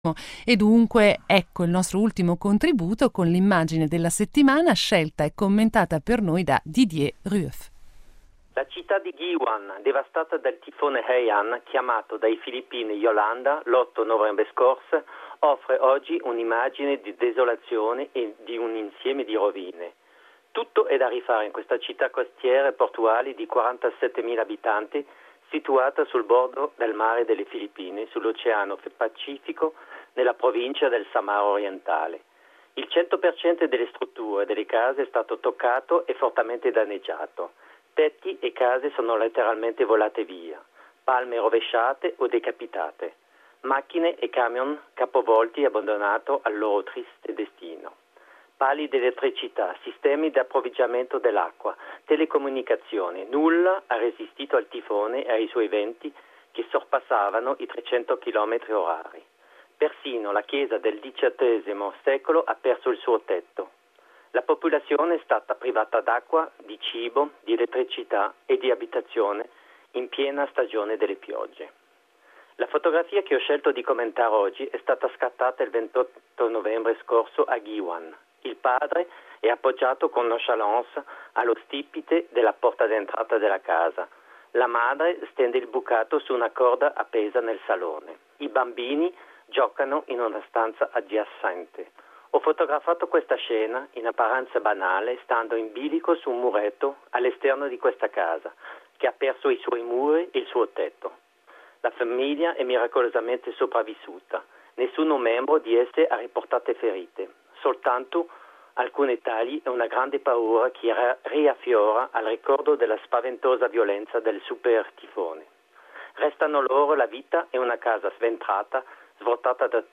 A radio comment about the image “Philippines Guiuan”.